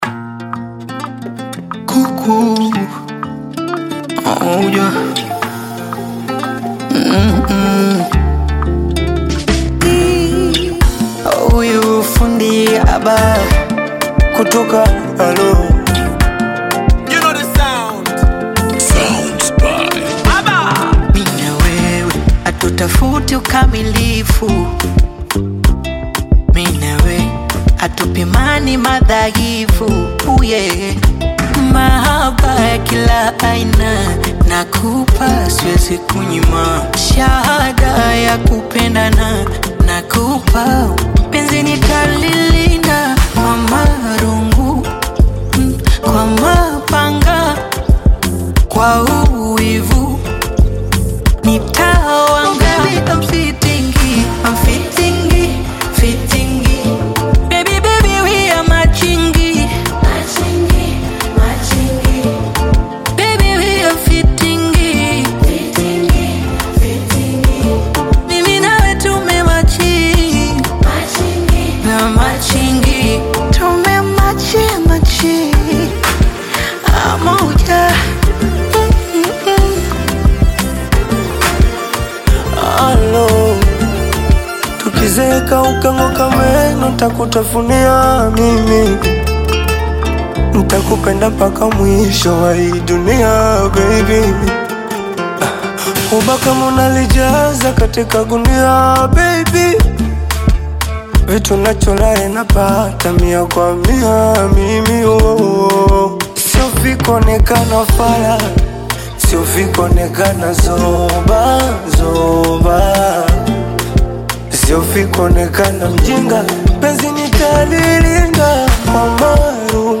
romantic Afro-Pop single
Genre: Bongo Flava